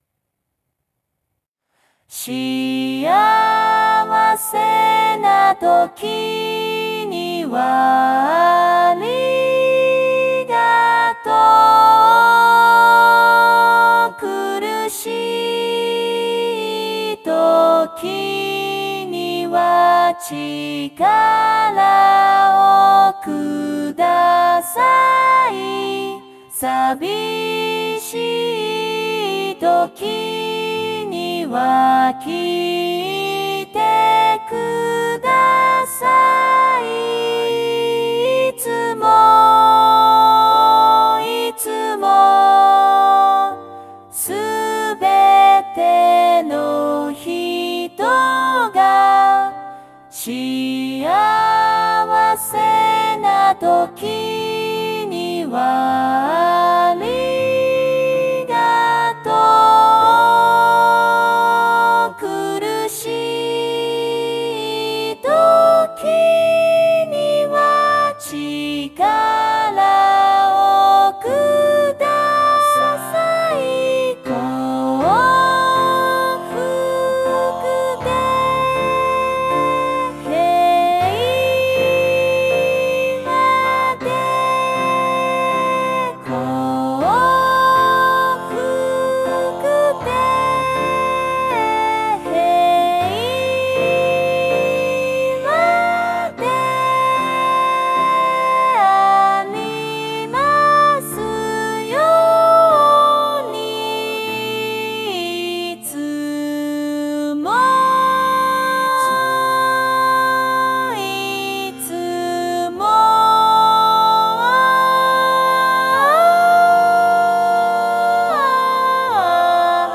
音取り音源(歌詞付き)